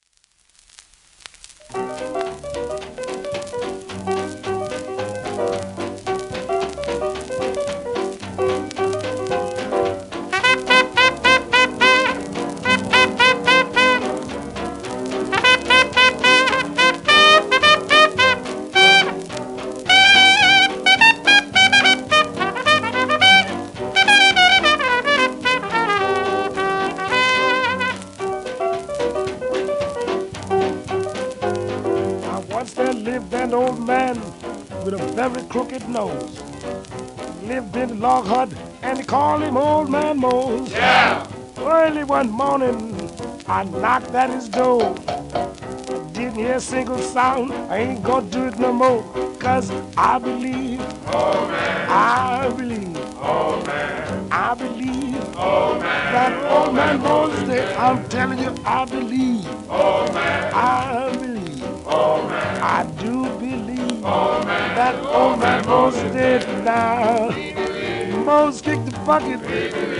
1935年N.Y録音